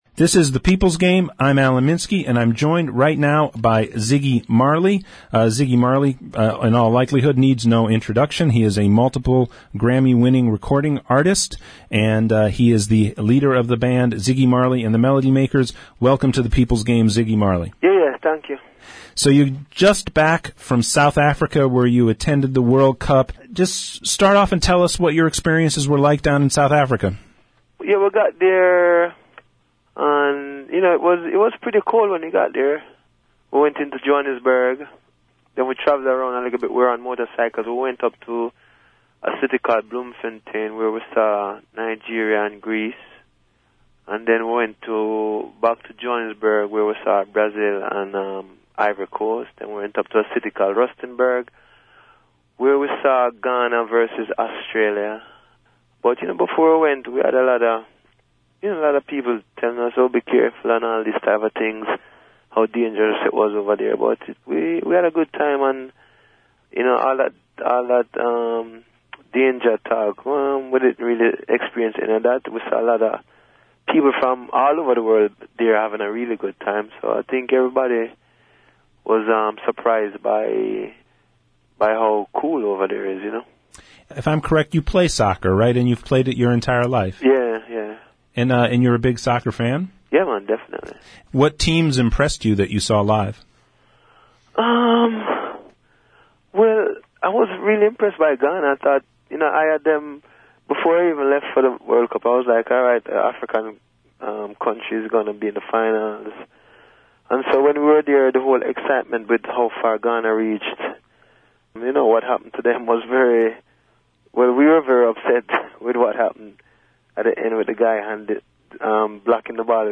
Ziggy Marley Interview – The People’s Game Radio